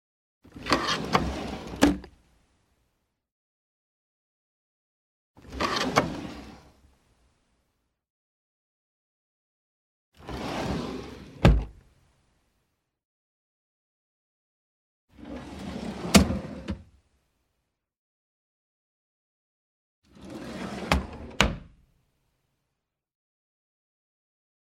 Звук в деревянном письменном столе открыли ящик